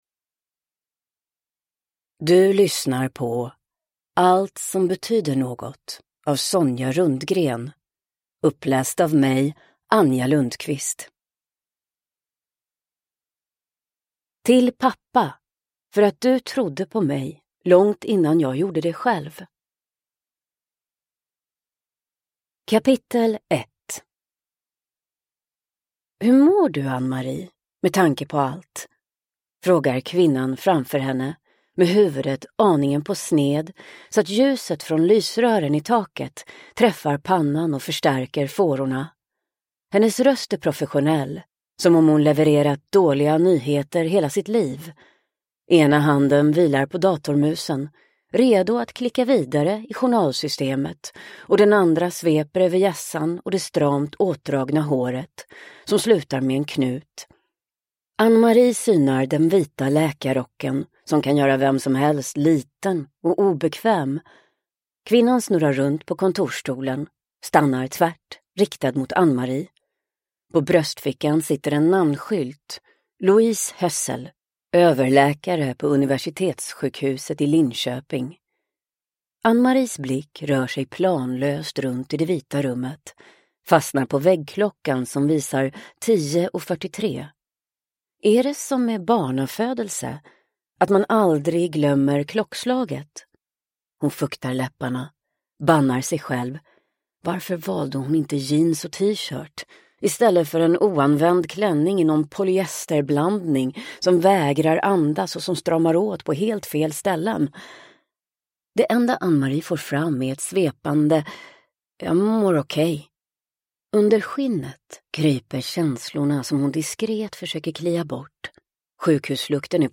Allt som betyder något / Ljudbok